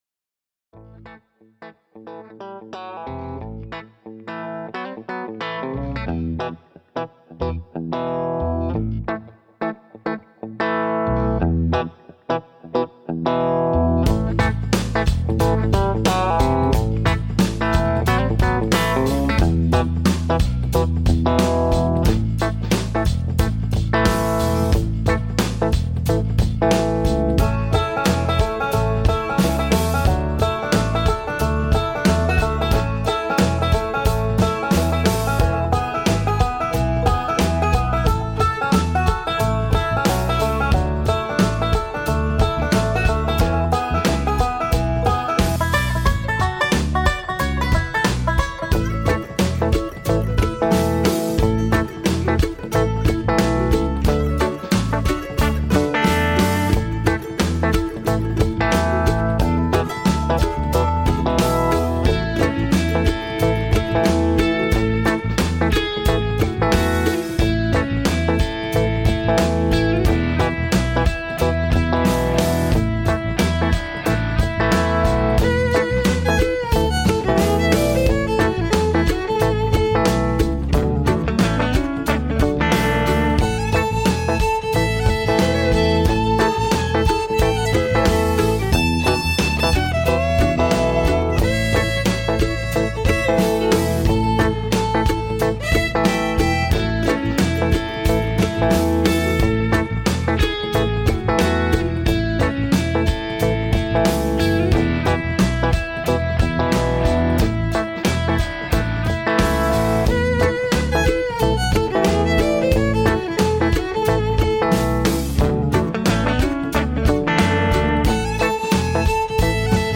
This episode plays several sound clips from the PRQ Timelines Show from January 25th, 2025